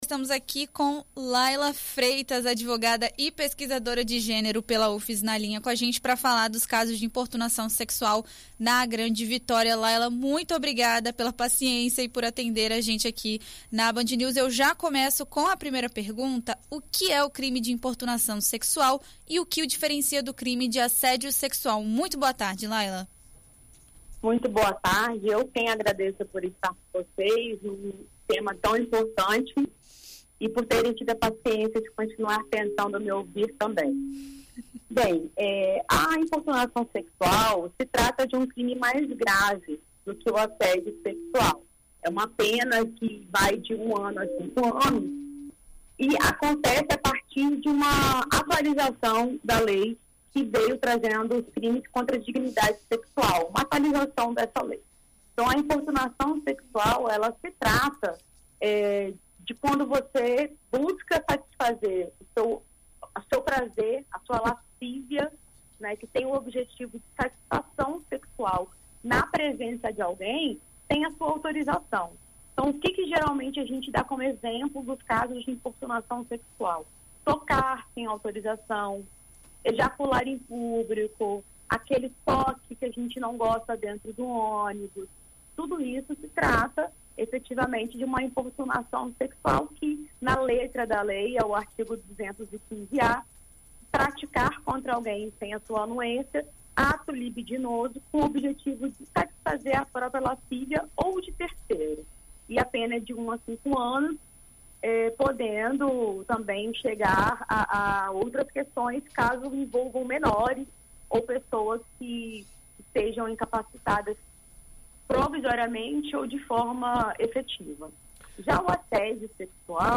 Em entrevista à BandNews FM ES nesta quarta-feira (13)